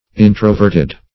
introverted - definition of introverted - synonyms, pronunciation, spelling from Free Dictionary
introverted \in"tro*vert`ed\ adj.